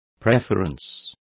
Complete with pronunciation of the translation of preferences.